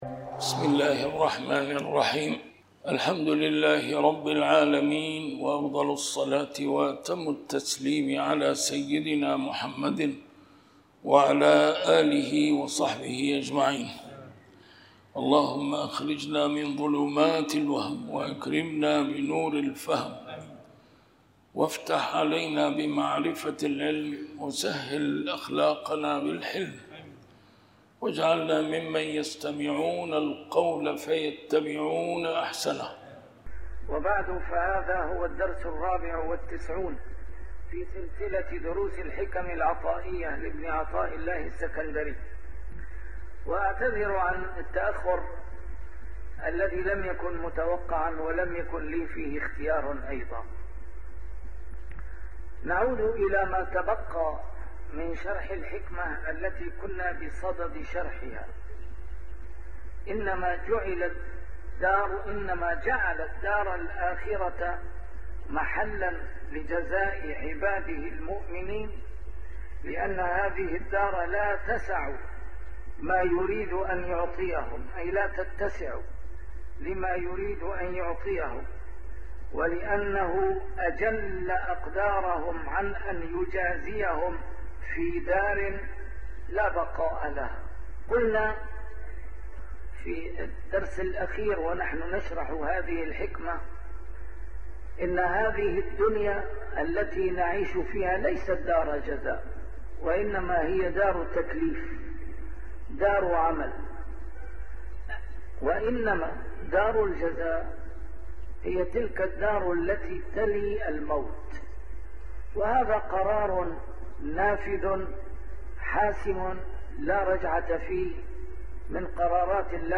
A MARTYR SCHOLAR: IMAM MUHAMMAD SAEED RAMADAN AL-BOUTI - الدروس العلمية - شرح الحكم العطائية - الدرس رقم 94 شرح الحكمة 71